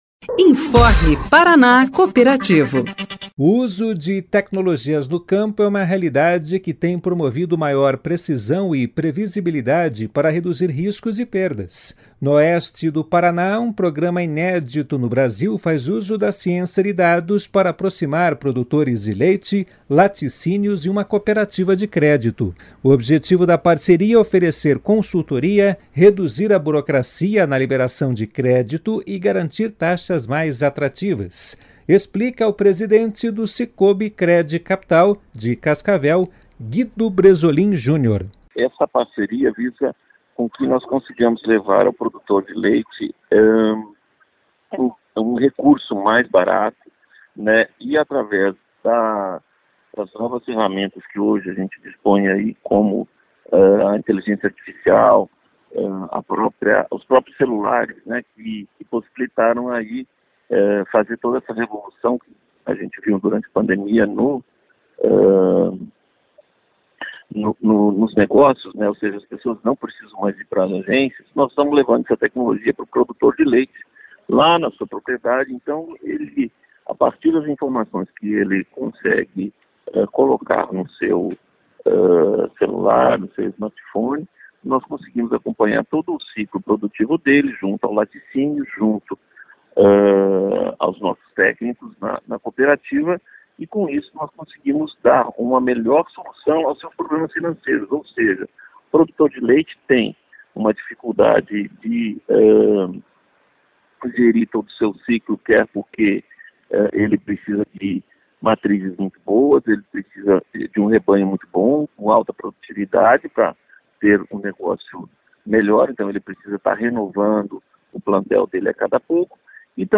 Notícias Rádio Paraná Cooperativo